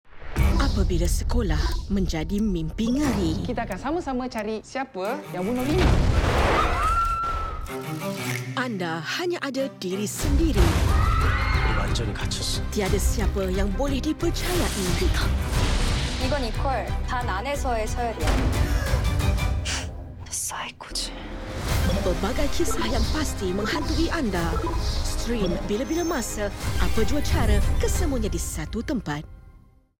Female
Sekolah Psiko (Malaysia/Korea/Japan) Thriller